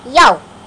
Yo (cartoon) Sound Effect
Download a high-quality yo (cartoon) sound effect.
yo-cartoon-1.mp3